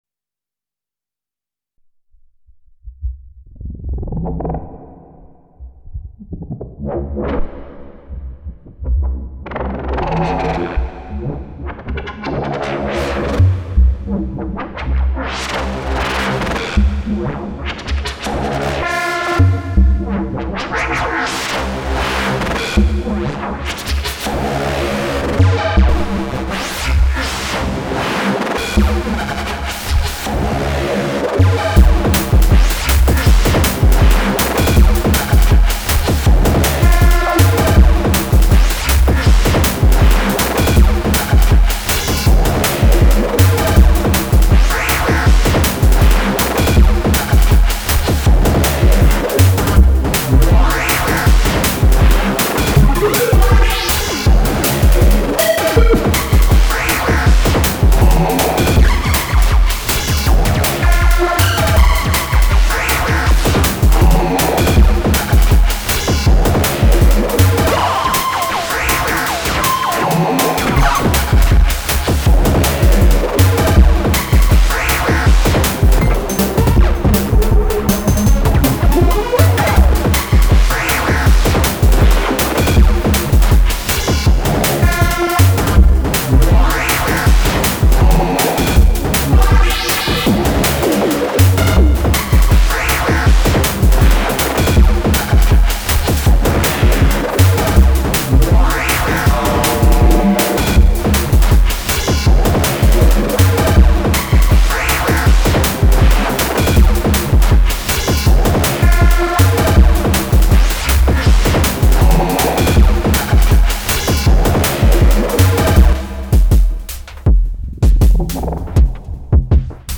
Metallic DnB bass on the DN - Digitone/Digitone Keys - Elektronauts
DnB bass attempt, from scratch.
Gnarly.